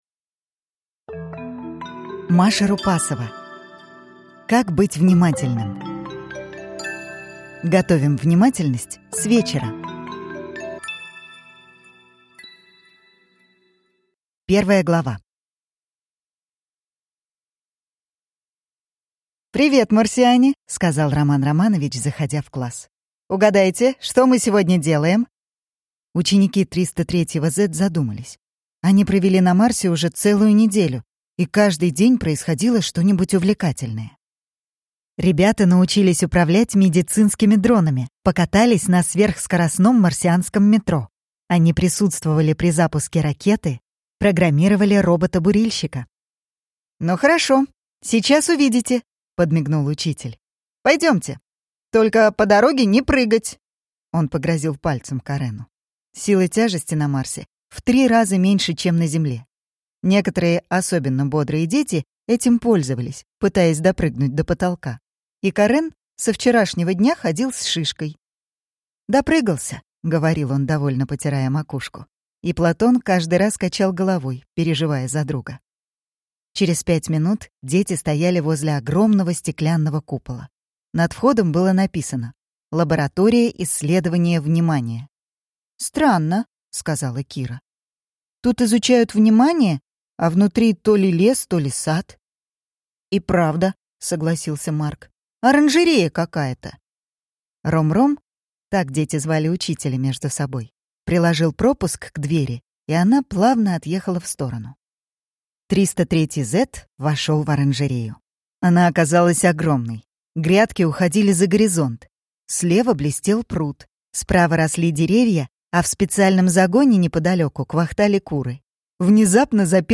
Аудиокнига Как быть внимательным. Готовим внимательность с вечера | Библиотека аудиокниг